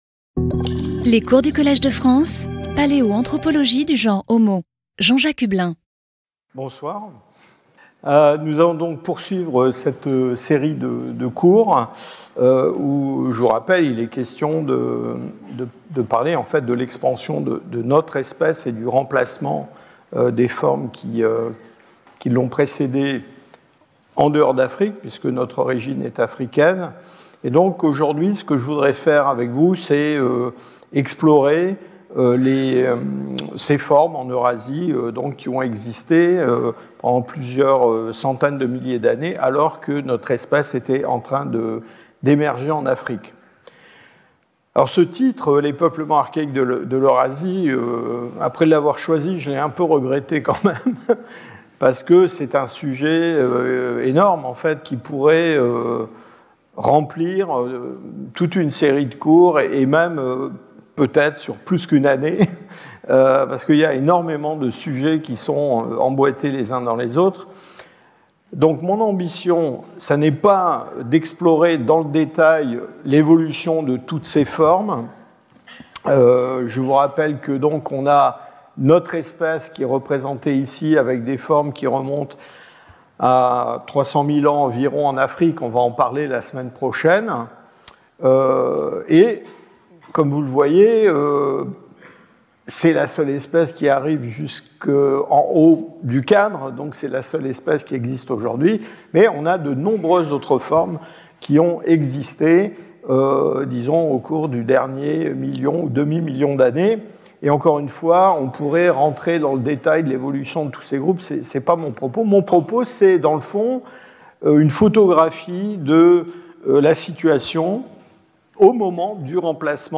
Speaker(s) Jean-Jacques Hublin Professor at the Collège de France
Lecture